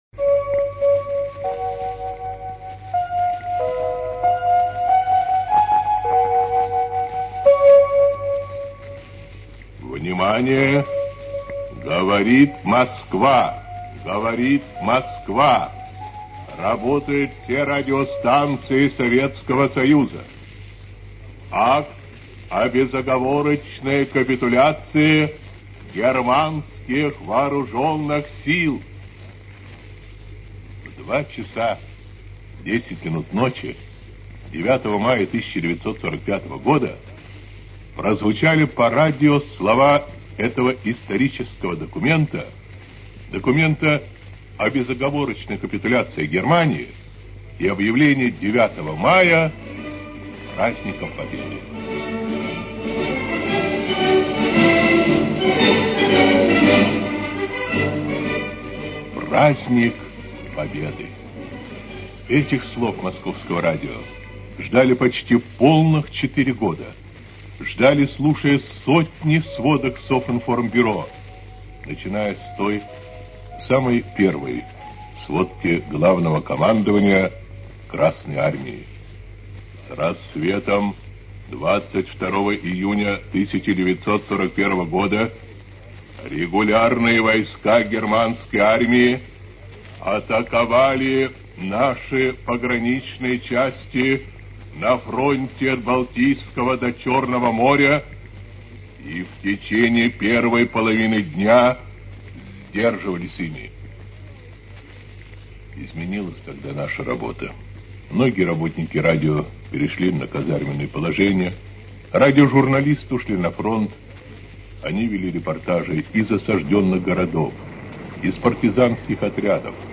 Вспоминает Юрий Левитан.